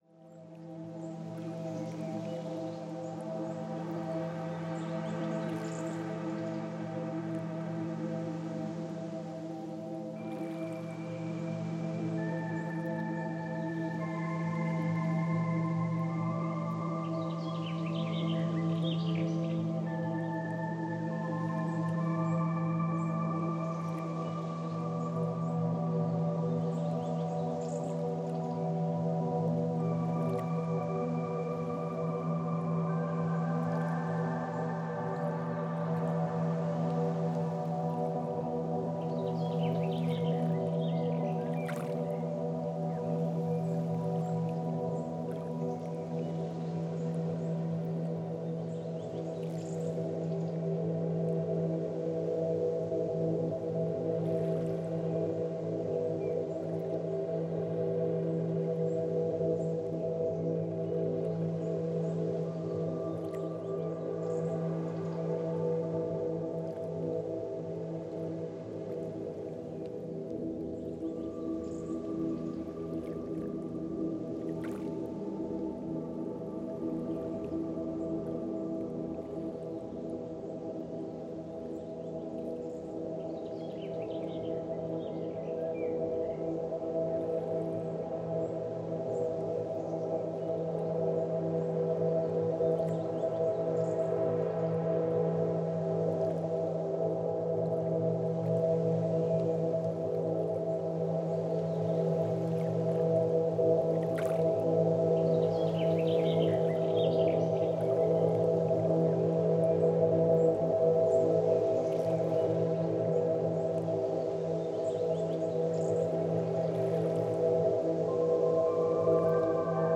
des sons apaisants
une sélection de belles ambiances sonores